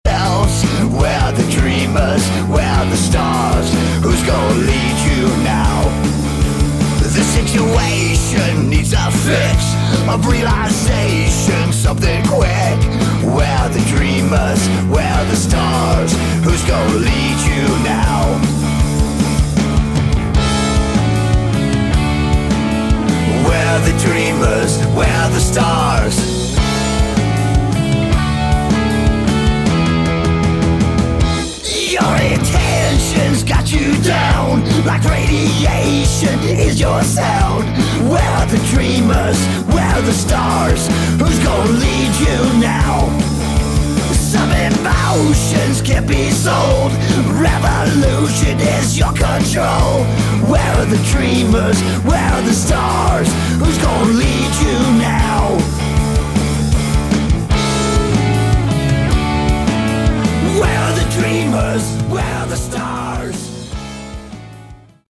Category: Sleaze Glam/Punk
lead vocals, guitars
bass, piano, mandolin, percussion, backing vocals
drums, backing vocals
guitar, ebow, backing vocals
acoustic guitar, electric steel, backing vocals